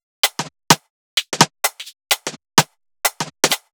Index of /musicradar/uk-garage-samples/128bpm Lines n Loops/Beats